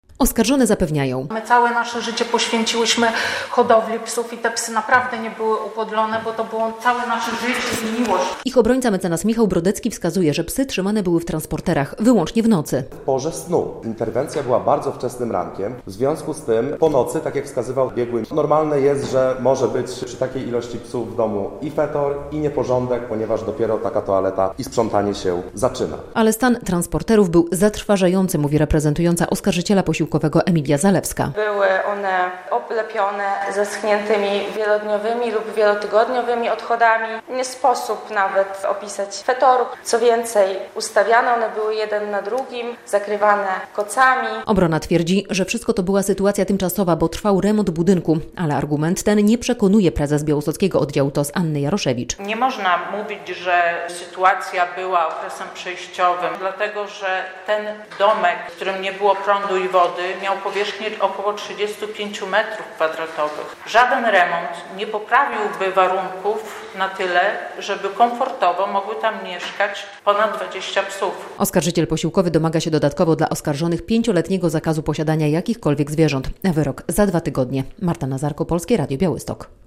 Rozprawa apelacyjna oskarżonych o znęcanie się nad psami - relacja